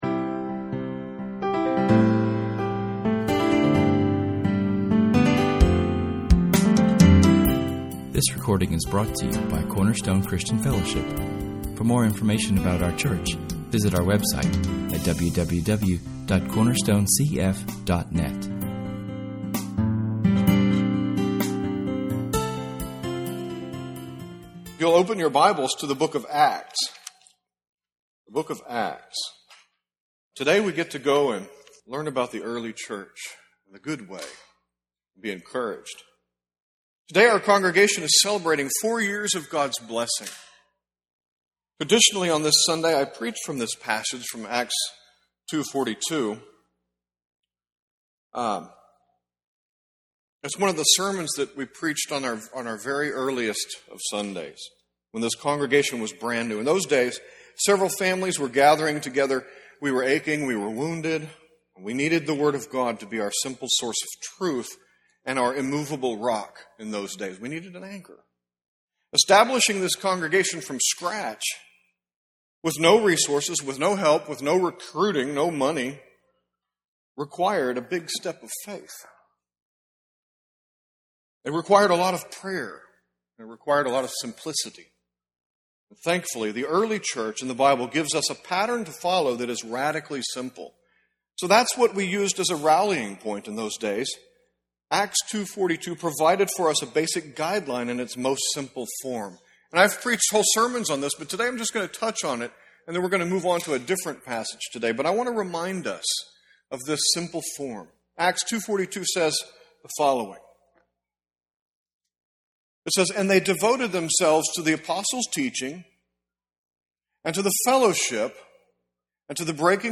Our sermon on this Anniversary Sunday is from [esvignore]Acts 2:42[/esvignore], as is our birthday tradition, but we examine [esvignore]Jeremiah 6:16[/esvignore] as well, and in this message, we focus more on five points from that text to show how [esvignore]Acts 2:42[/esvignore] (our founding passage) relates. Both verses are very important to our church.